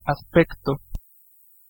Ääntäminen
France: IPA: [ɛk.spʁɛ.sjɔ̃]